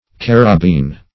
carabine - definition of carabine - synonyms, pronunciation, spelling from Free Dictionary Search Result for " carabine" : The Collaborative International Dictionary of English v.0.48: Carabine \Car"a*bine\ (k[a^]r"[.a]*b[imac]n), n. (Mil.)